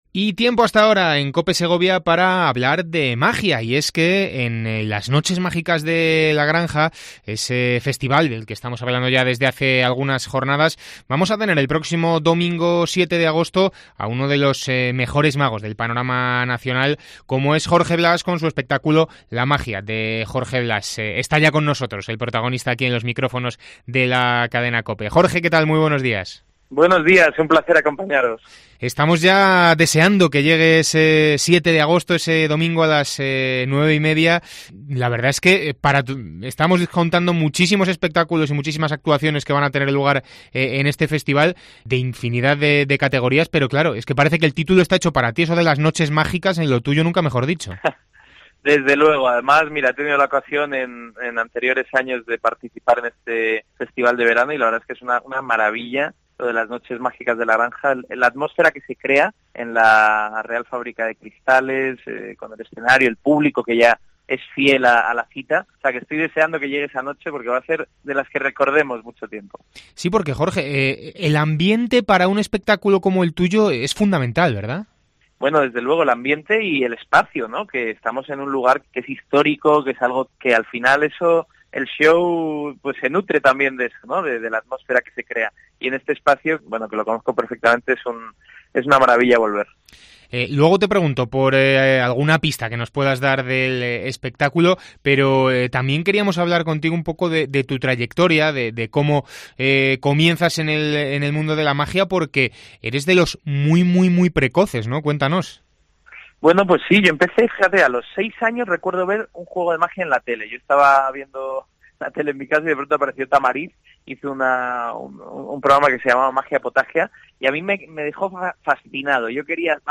Entrevista a Jorge Blas, Mago